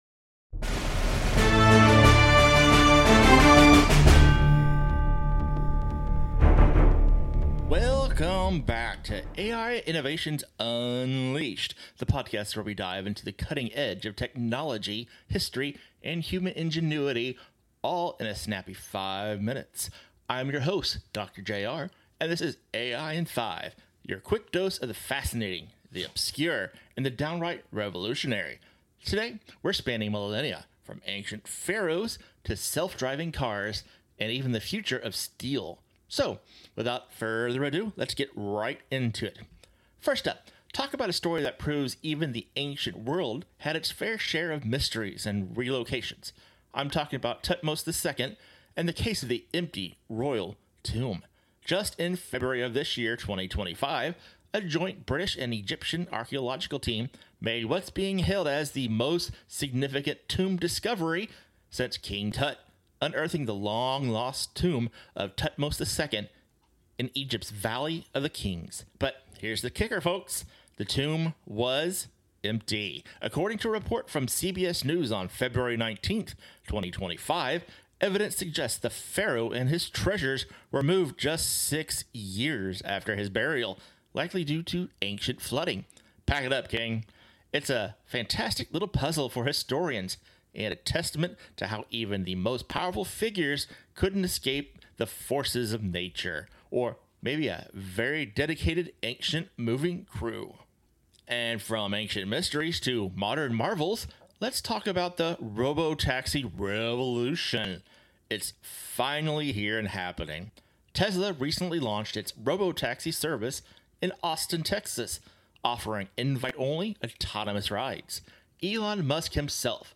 Get your dose of witty banter, energetic insights, and meaningful stories, all in just five minutes!